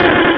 Cri de Kecleon dans Pokémon Rubis et Saphir.